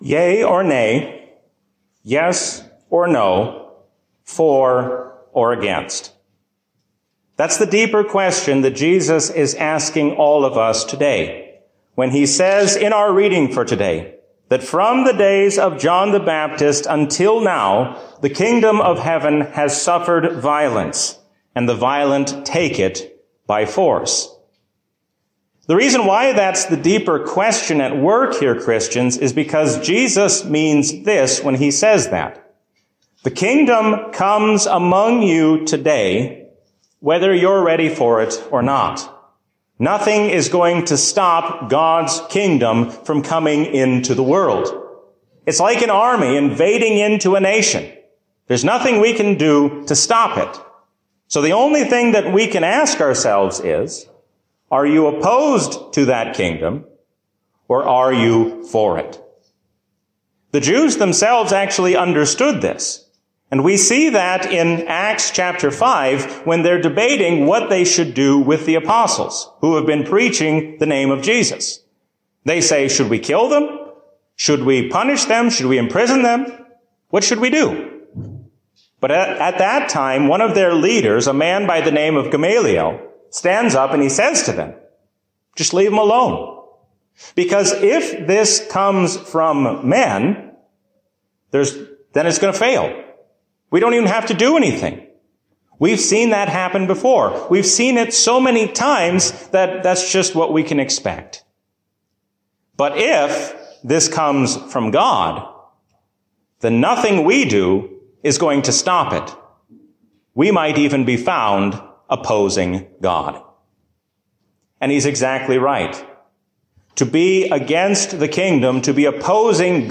A sermon from the season "Easter 2022." Let us be Christians not only in our words, but also in what we do.